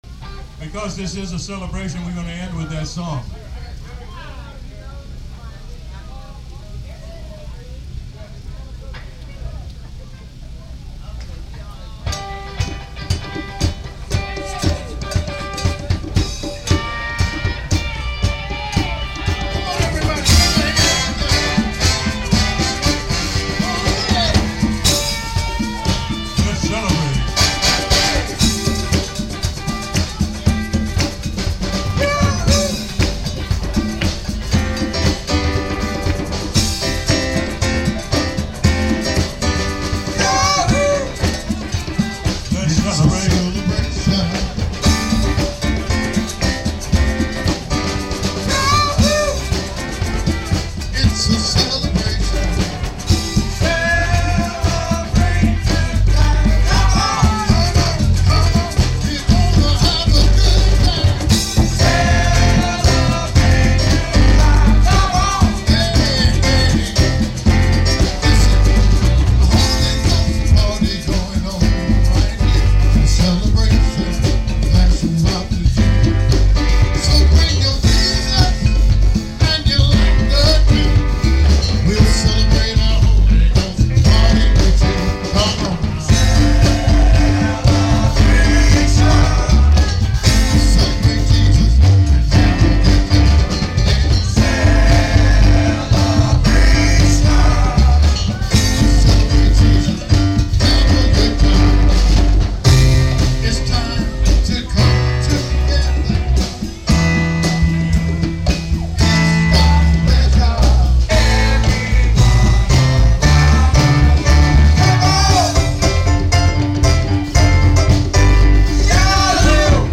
The First Men of Promise on YouTube The First Men of Promise - Recorded Live at Frank Ball Park Juneteenth Celebration 6/16/2018.